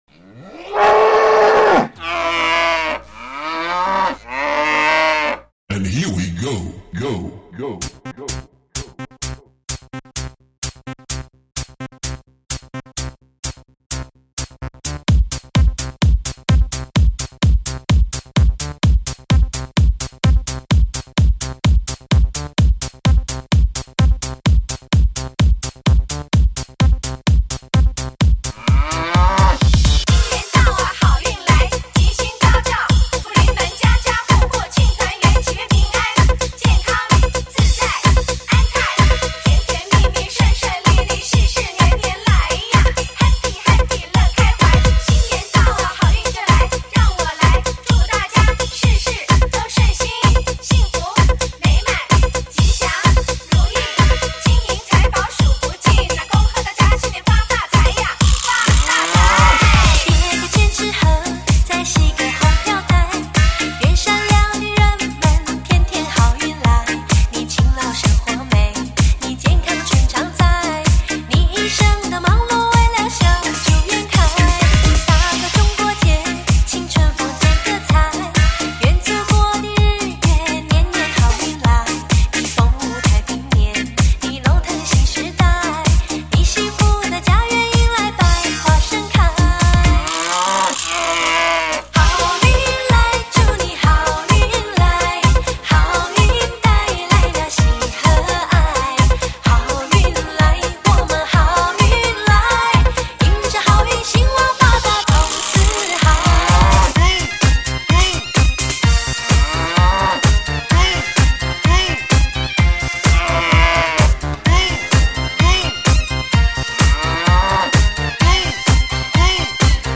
贺年好歌融合嗨曲元素 量身打造新年特辑
强劲的DJ节拍，伴随春节喜庆的气氛，让你得意洋洋！